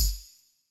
tamborine.ogg